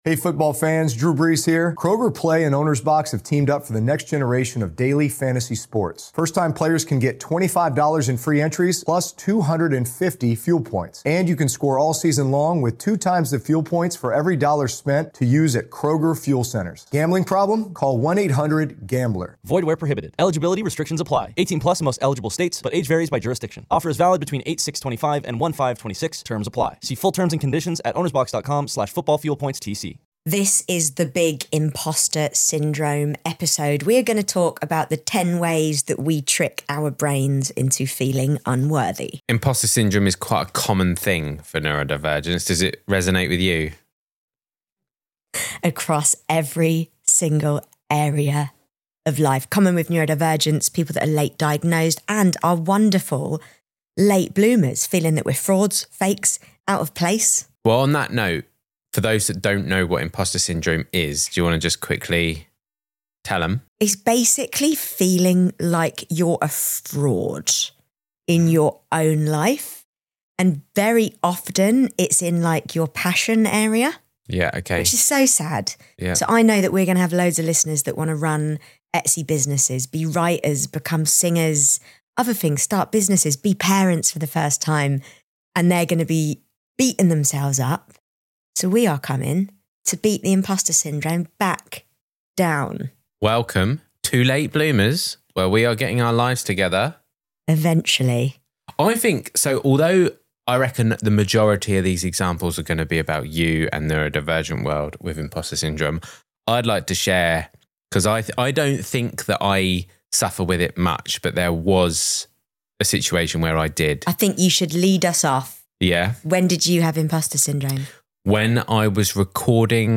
Tender, honest, and funny in the very human way only these two do it — this episode is a reminder that you don’t have to feel like you belong to actually belong.